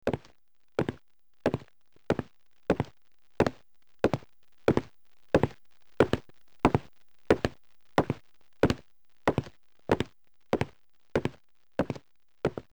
Dóna la impressió que una persona que està caminant s'acosta, passa pel vostre cantó i s'allunya.